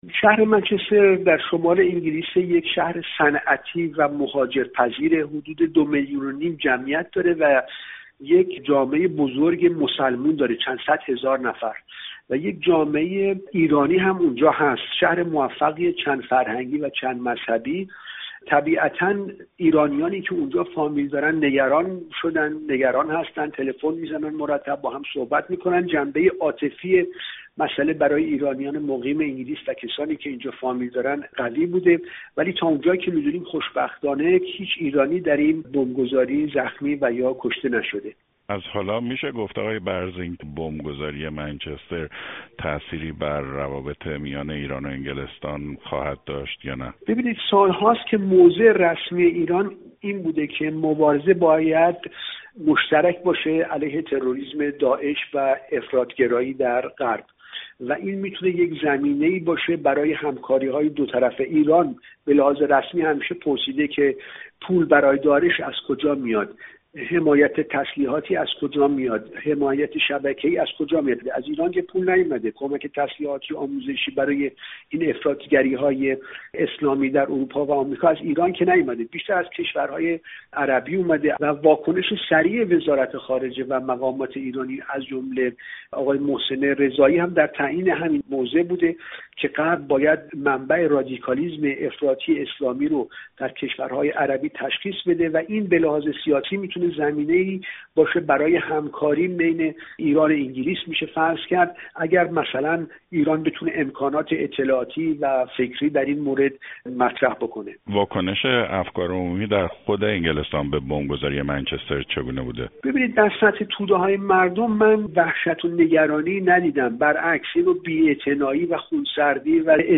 پیامدهای بمب‌گذاری منچستر در گفت‌و‌گو